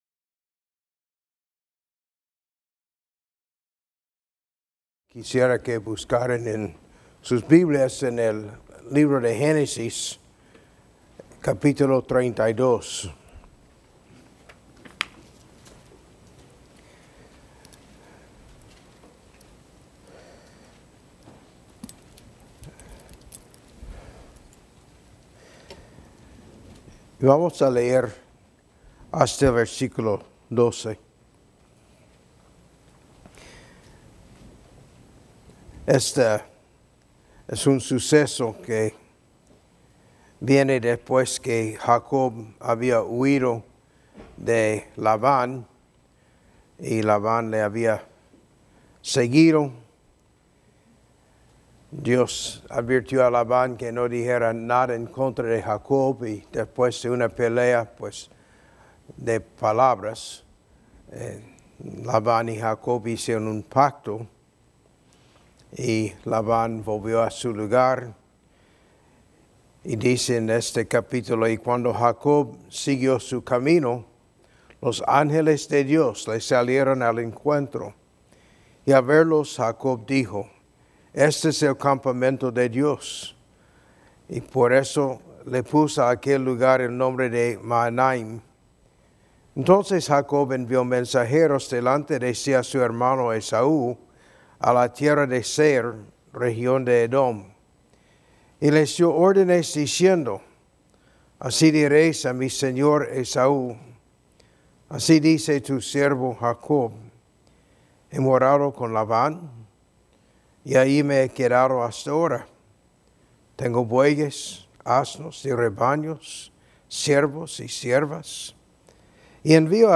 Meditación sobre Génesis 32:1-12 – Conferencia Pastoral